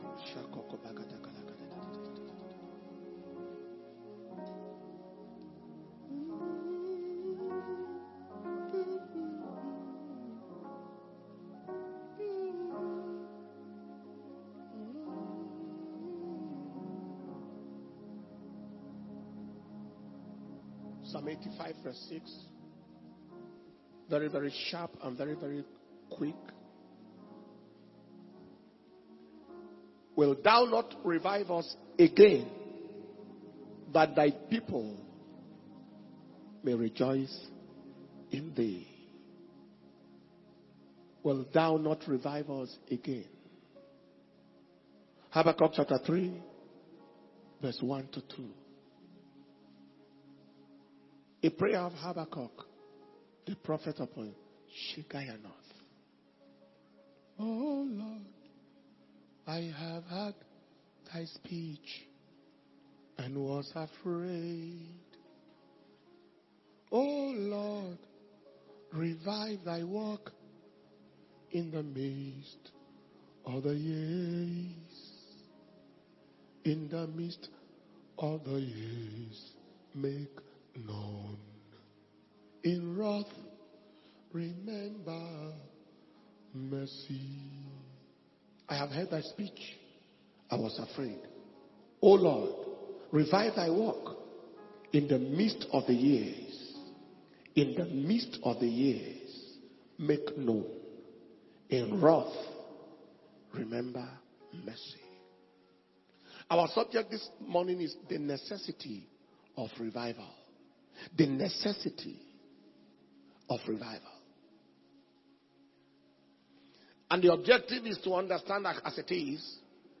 International Flaming Fire Conference 2023 - Day 2 Morning Session